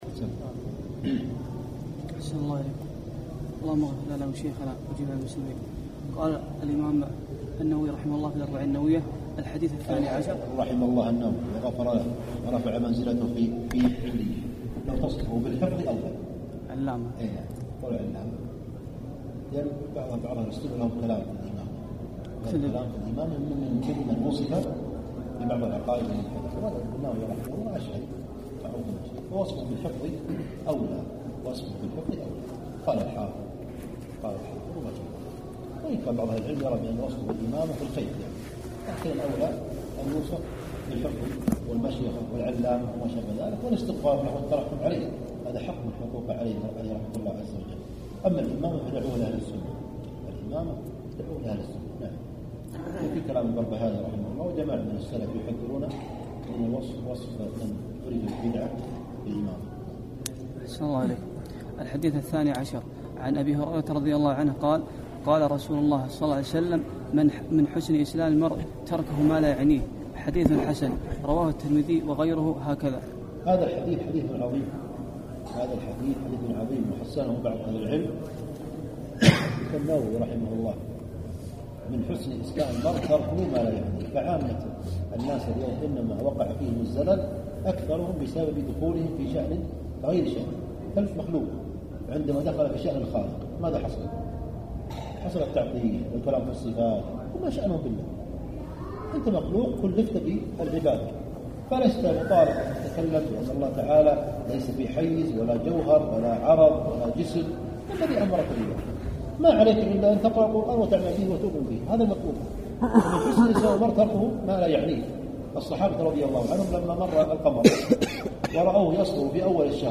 كان الدرس في السعودية في مدينة الطائف بتاريخ 2/4/1435هـ